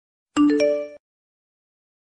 Catégorie SMS